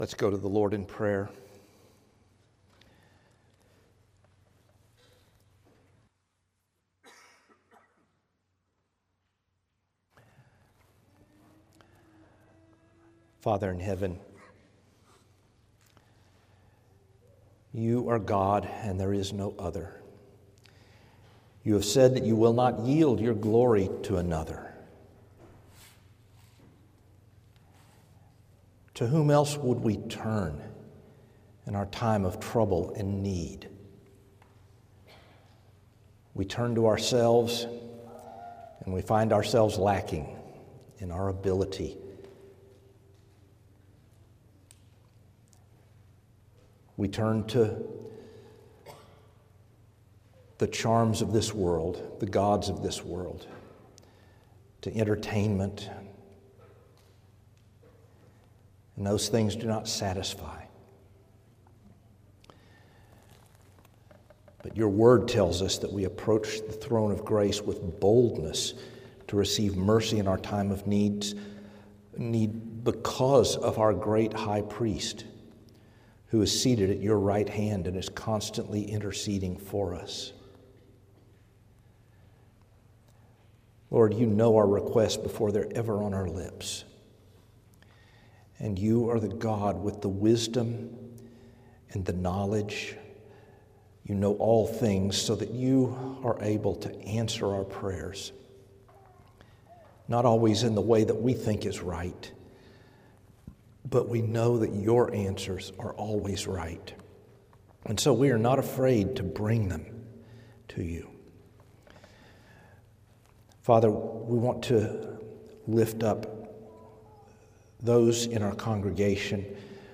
From Series: "Standalone Sermons"